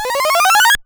その他の効果音 試聴ダウンロード ｜ seadenden 8bit freeBGM